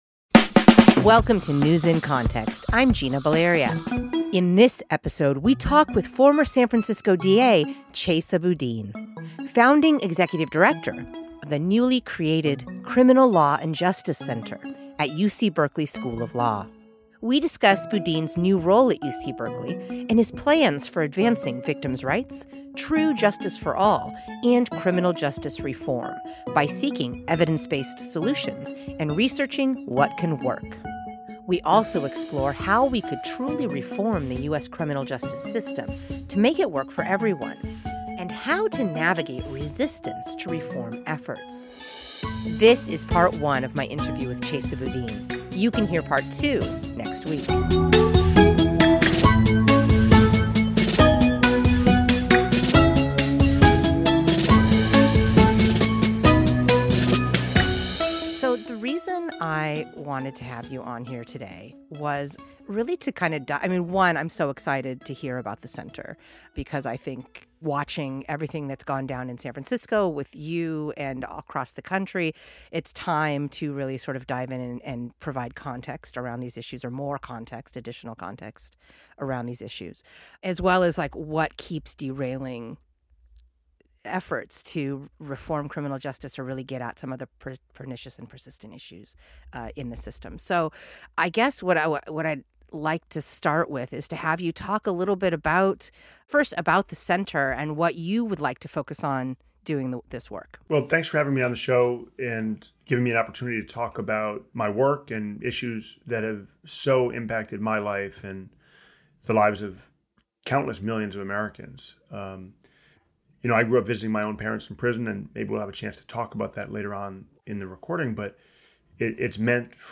This is Part 1 of my interview with Chesa Boudin.